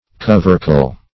Search Result for " covercle" : The Collaborative International Dictionary of English v.0.48: Covercle \Cov"er*cle\ (k?v"?r-k'l), n. [OF. covercle, F. couvercle, fr. L. cooperculum fr. cooperire. See cover ] A small cover; a lid.